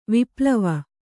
♪ viplava